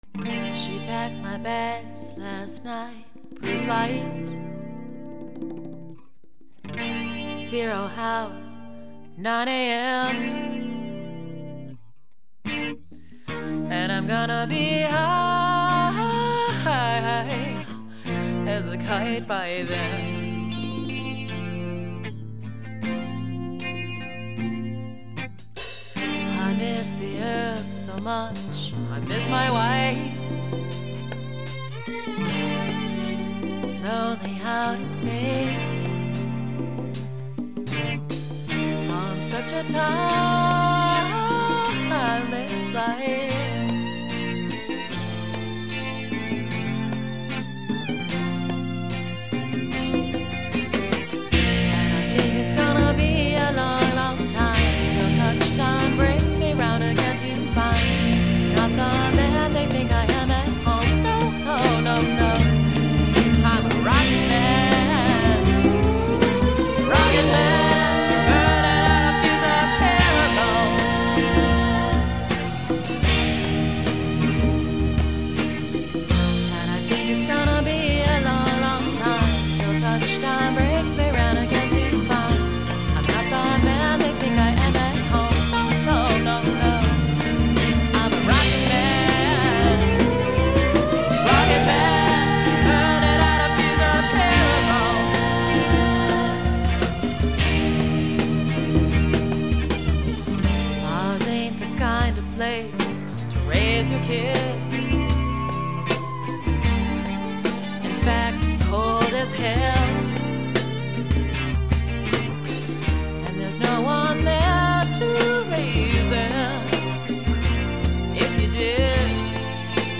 Here is the song they recorded.